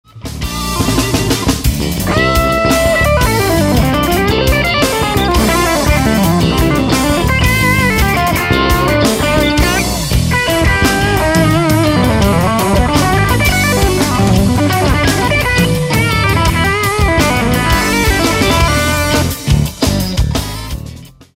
Keep in mind these are close miced with an SM57 and recorded on a home PC. No room tone at all....
Fuchs SLX30 just recorded, amp is cathode biased w/2X6L6